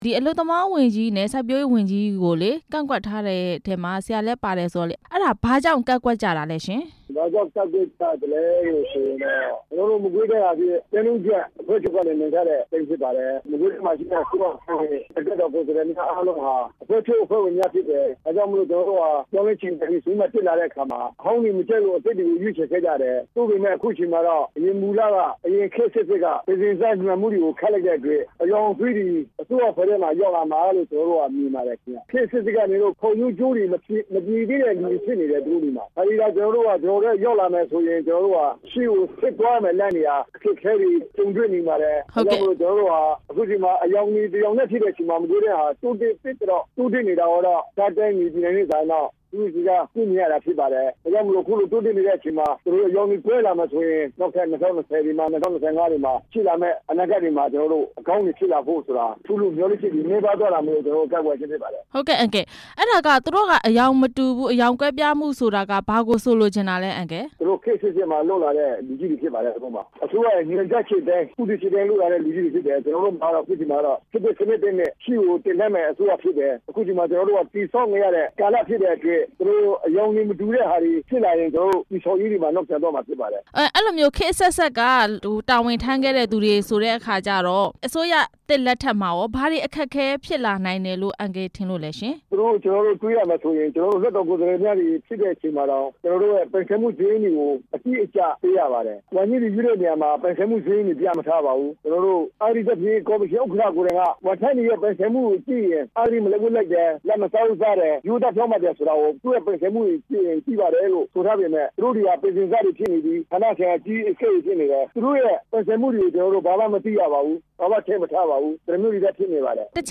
မကွေးတိုင်း ဝန်ကြီးနှစ်ဦး အဆိုပြုခံရသူတွေကို ကန့်ကွက်တဲ့အကြောင်း မေးမြန်းချက်